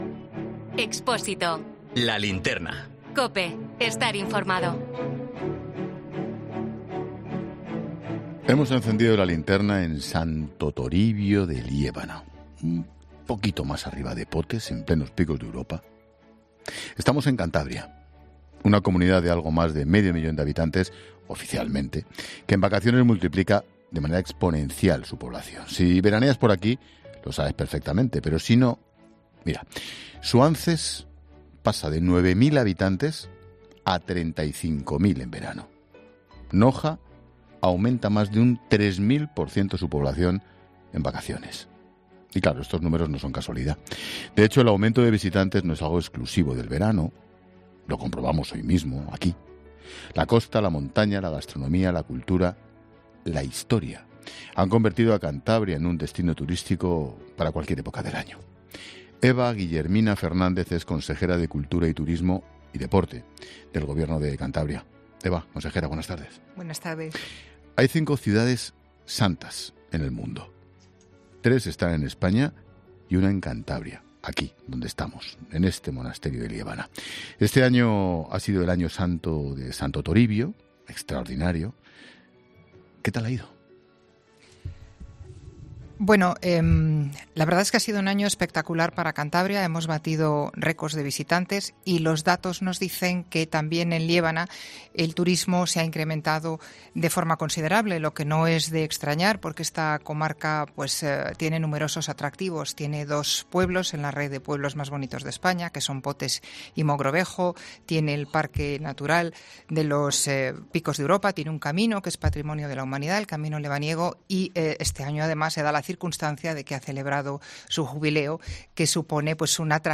Expósito habla en La Linterna con la consejera de Turismo de Cantabria, Eva Guillermina Fernández, desde el monasterio de Santo Toribio de Liébana